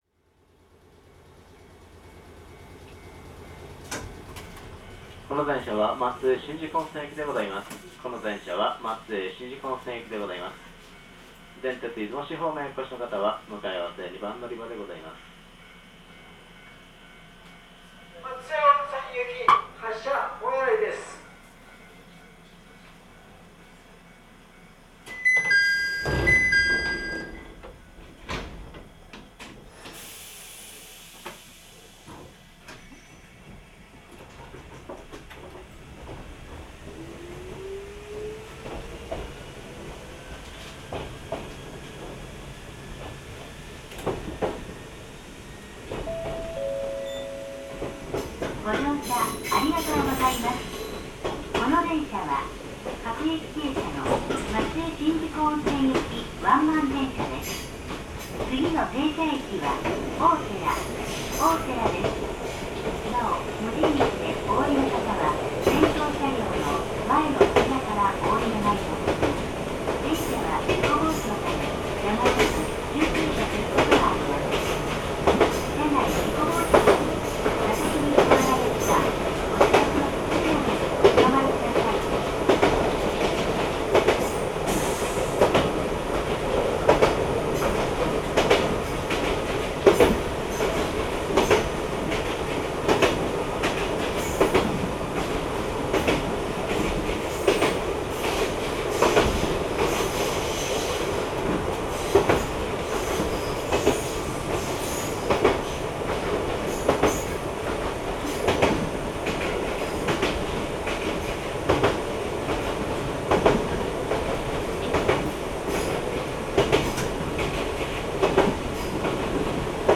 一畑電車 2100系 ・ 走行音(長区間) (64.4MB*) ←new!!! 収録区間：川跡→松江しんじ湖温泉 制御方式：抵抗制御 主電動機：MB-3054(75kW) 元京王5000系であり、1994(平成6)年から翌年にかけて導入された。
モーター音は営団3000系譲りの甲高い感じの音が特徴。昔の三菱モーターと言えばこんな感じの音でした。